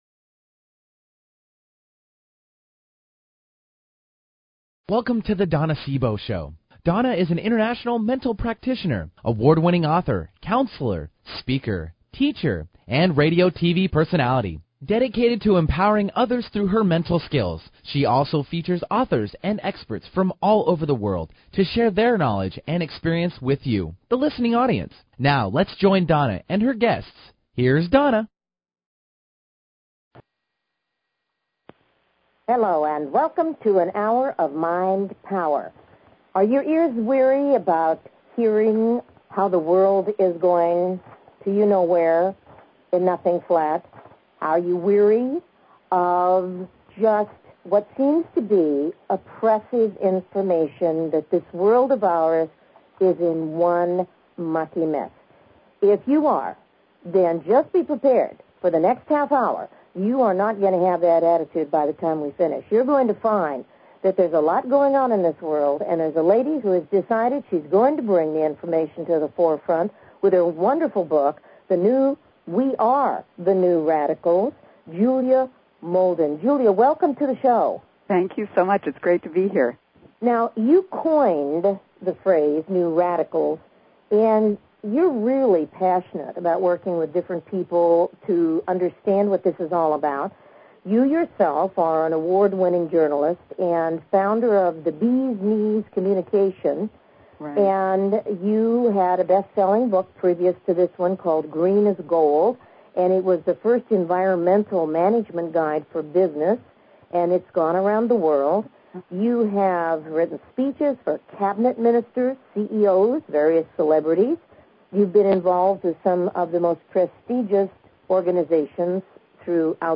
Her interviews embody a golden voice that shines with passion, purpose, sincerity and humor.